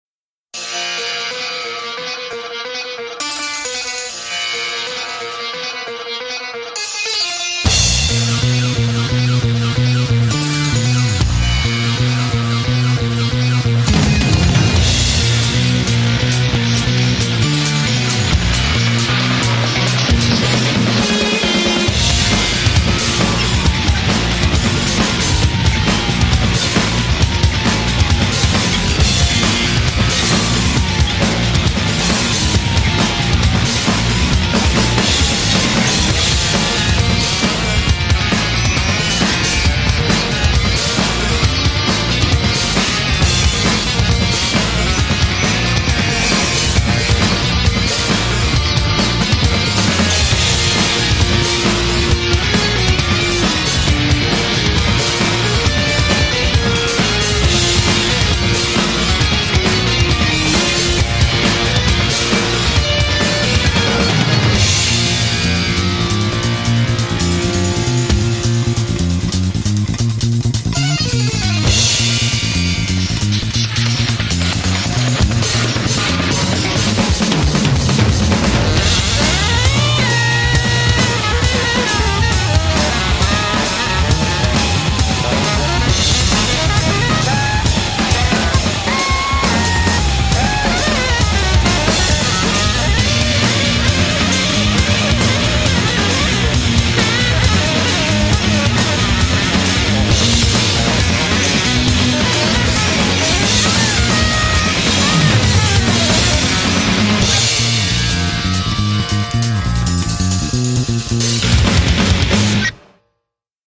BPM135
rockin’ desert vibe and a killer sax solo